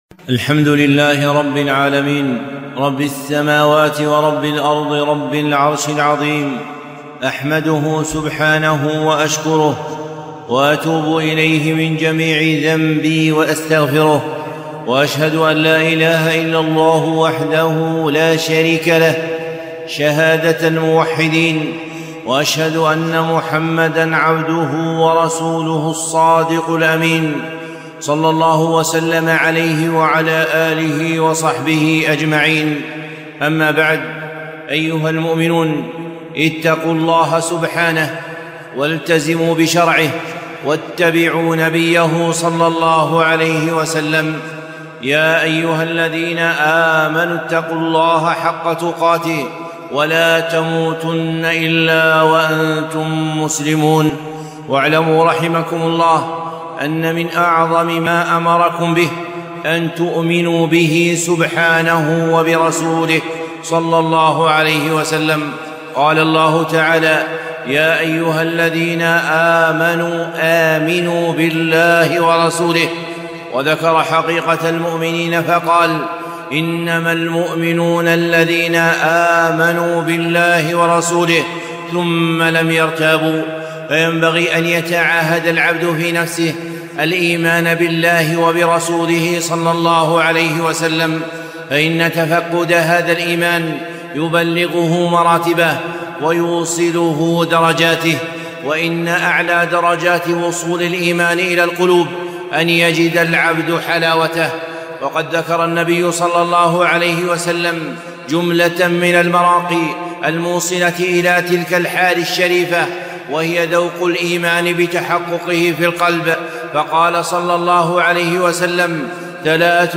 خطبة - ذوق الإيمان في حب الرحمن